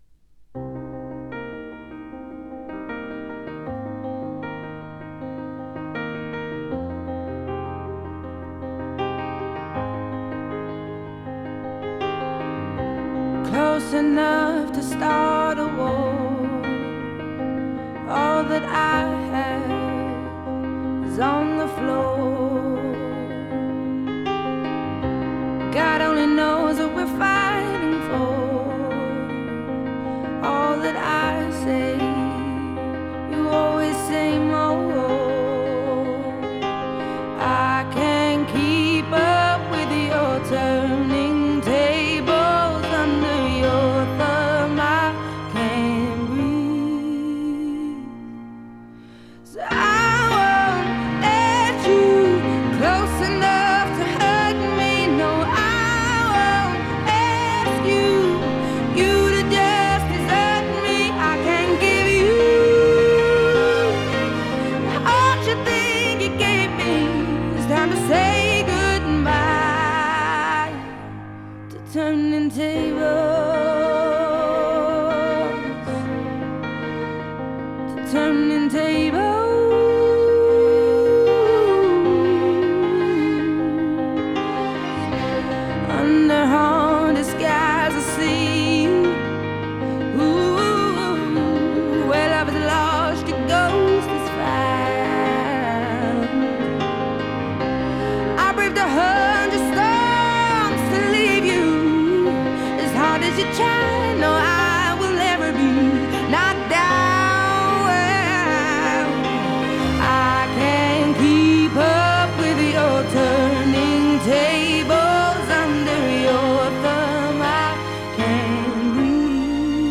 Genre: Pop, Blues